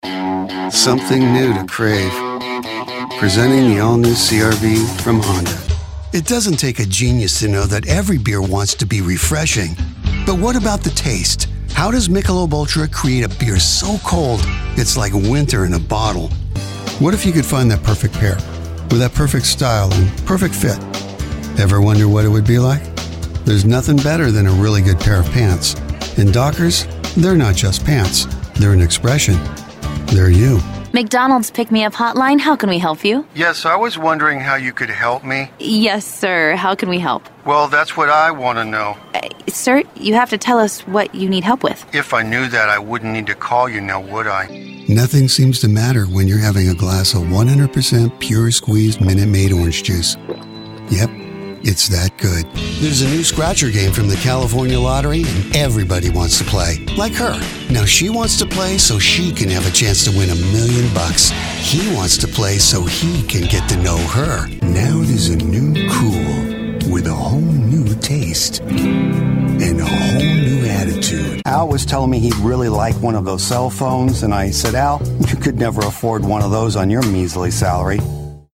Conversational Quirky Cool Dry Regular Guy